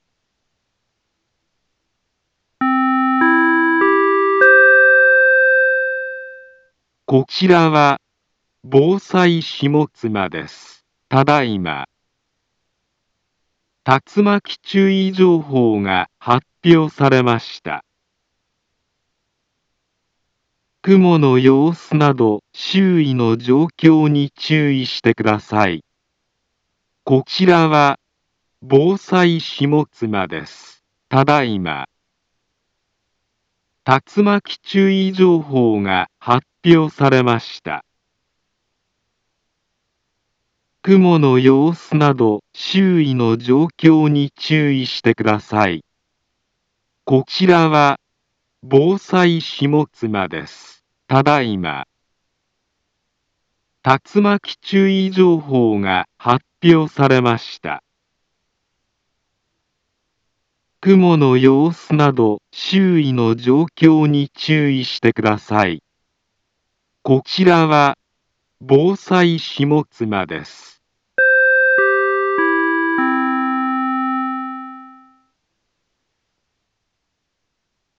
Back Home Ｊアラート情報 音声放送 再生 災害情報 カテゴリ：J-ALERT 登録日時：2025-03-19 08:38:28 インフォメーション：茨城県南部は、竜巻などの激しい突風が発生しやすい気象状況になっています。